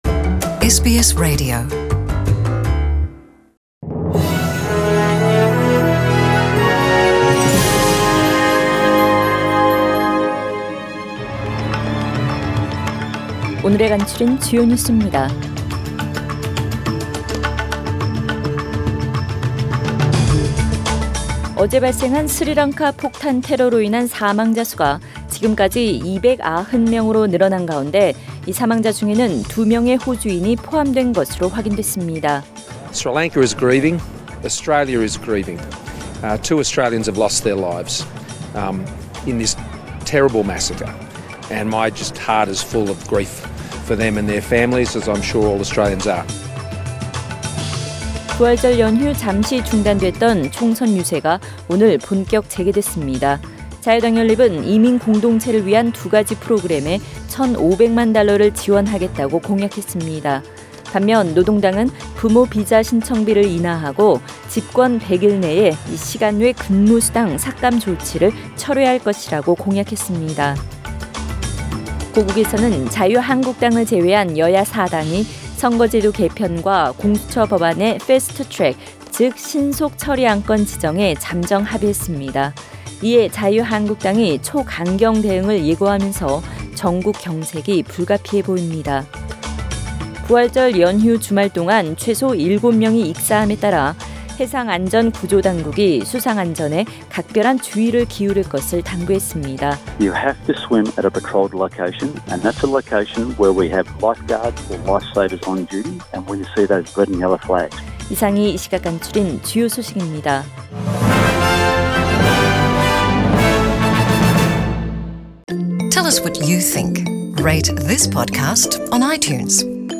SBS 한국어 뉴스 간추린 주요 소식 – 4월 22일 월요일